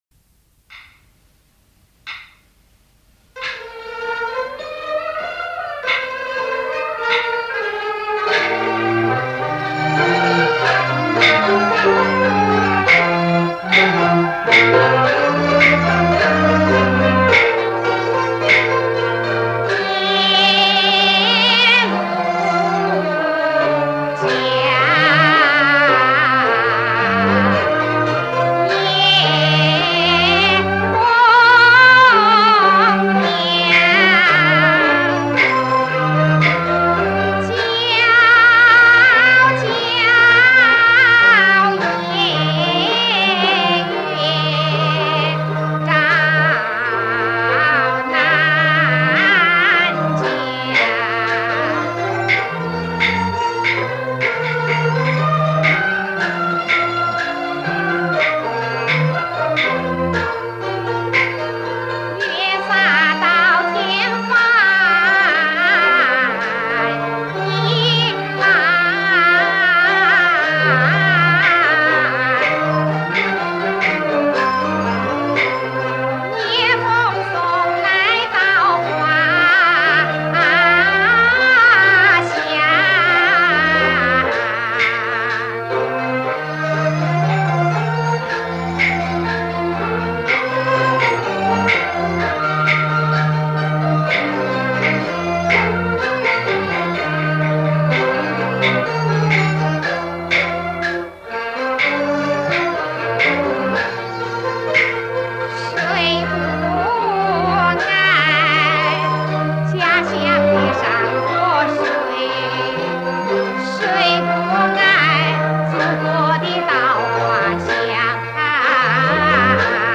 黄梅戏